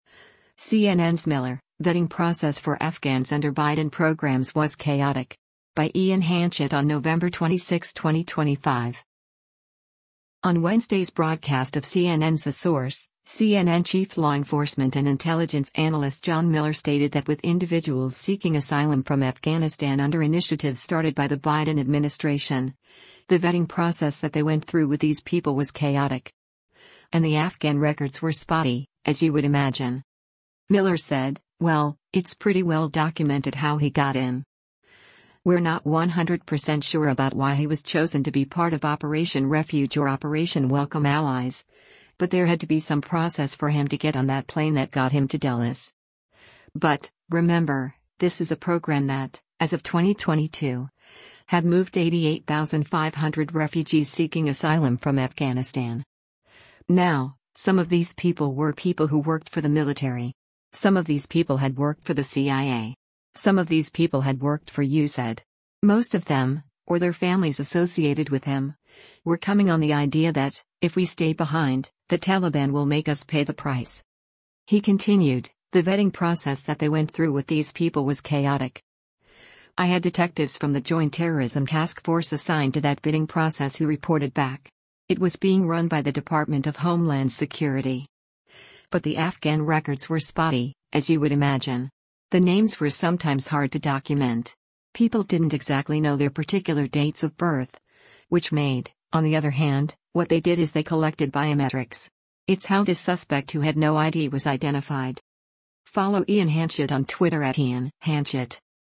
On Wednesday’s broadcast of CNN’s “The Source,” CNN Chief Law Enforcement and Intelligence Analyst John Miller stated that with individuals seeking asylum from Afghanistan under initiatives started by the Biden administration, “The vetting process that they went through with these people was chaotic.” And “the Afghan records were spotty, as you would imagine.”